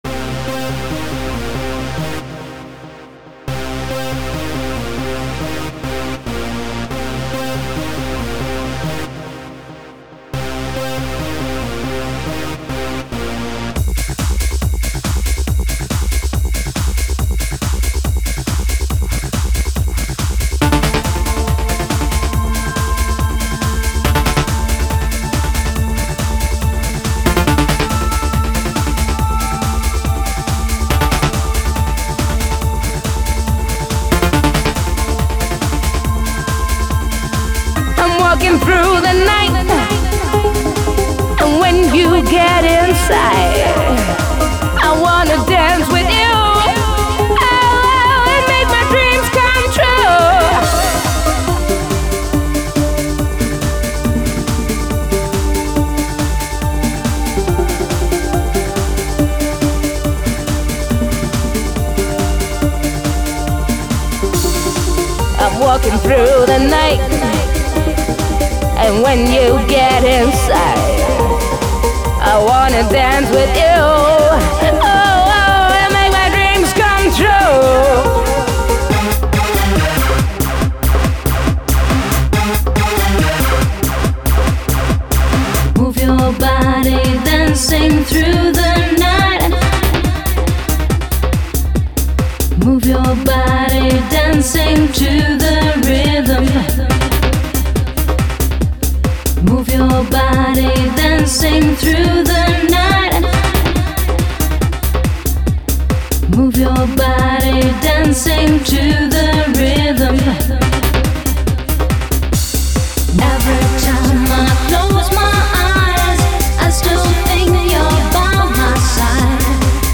Techno χορευτική διάθεση